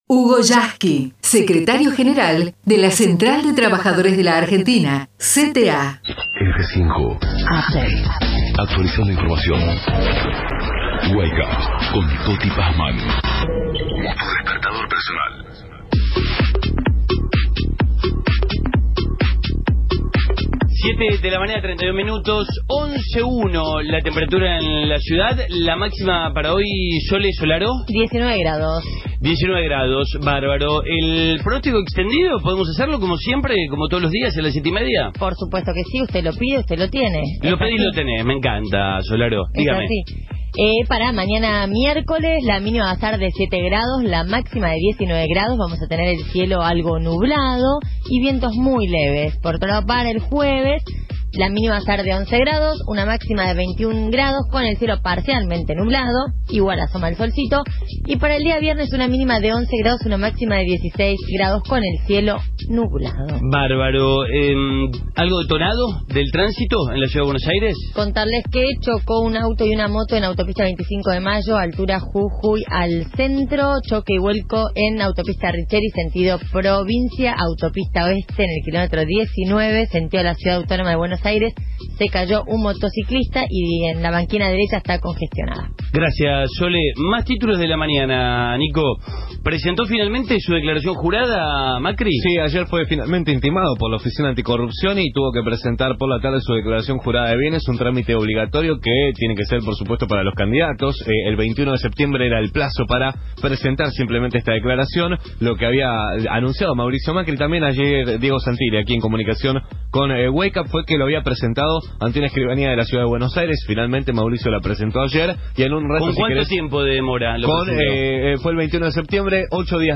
HUGO YASKY (entrevista) FM DELTA
hugo_yasky_en_fm_delta.mp3